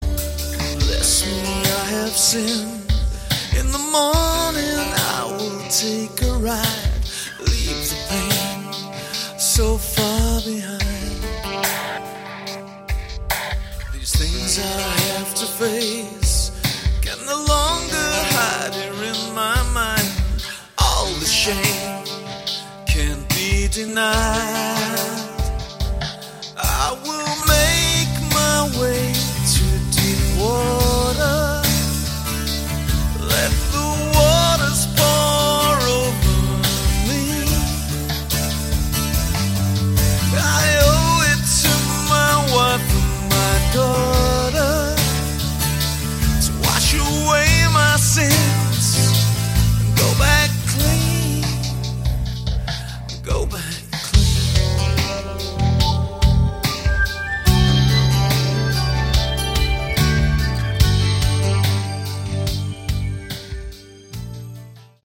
Category: AOR
Vocals
Drums, Percussion
Guitar, Keyboards, Vocals